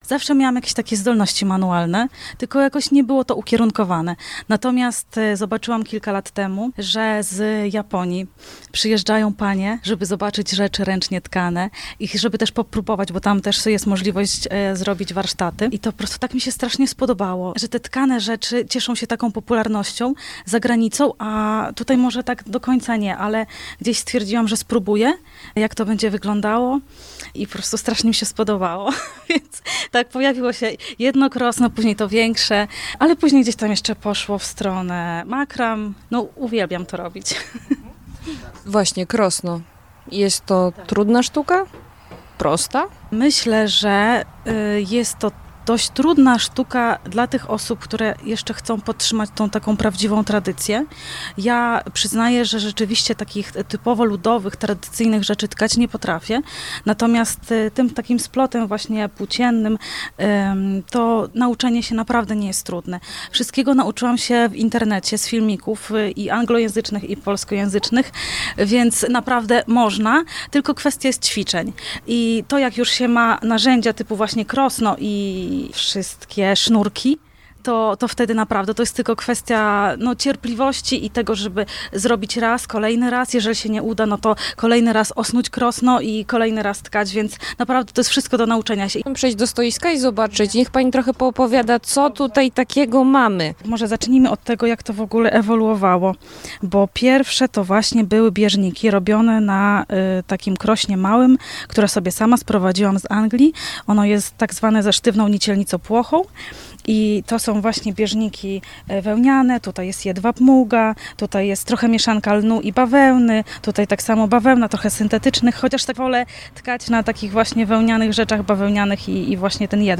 spotkała w Muzeum Wsi Lubelskiej podczas Odpustu Matki Boskiej Zielnej.